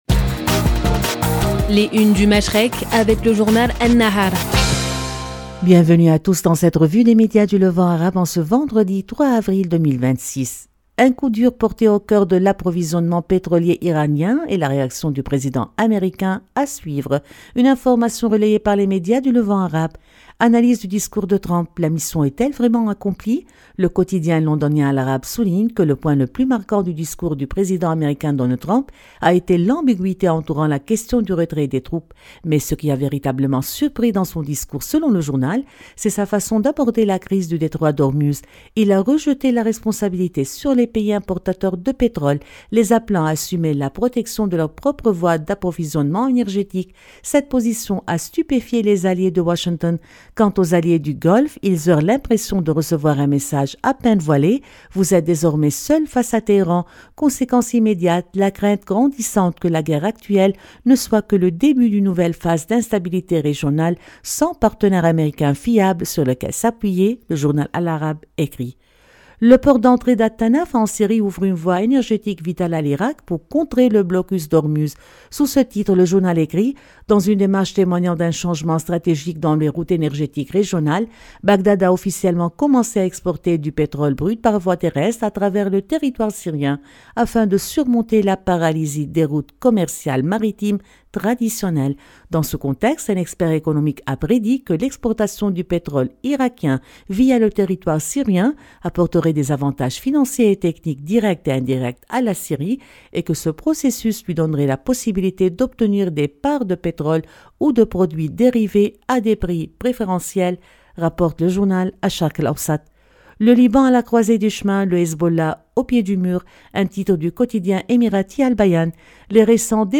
Revue de presse des médias Moyen-Orient